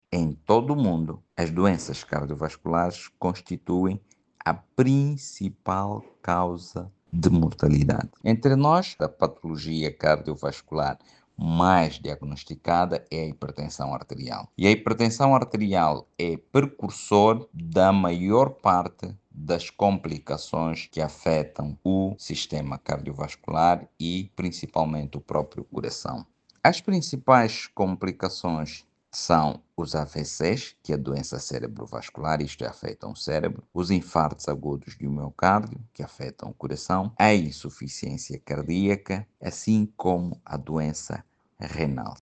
No dia mundial do coração, médico especialista lança alerta sobre principais riscos das doenças cardiovasculares